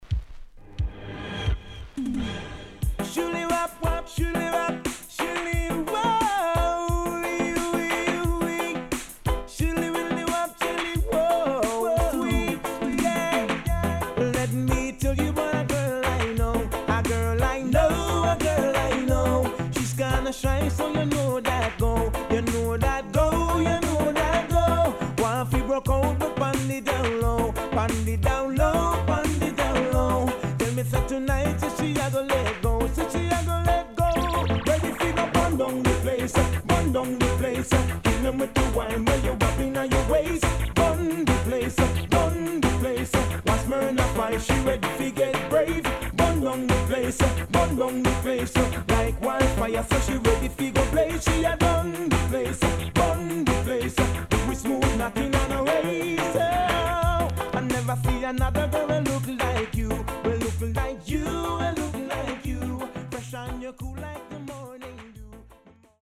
HOME > LP [DANCEHALL]
SIDE A:少しチリノイズ、プチノイズ入ります。